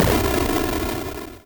magic_crumble.wav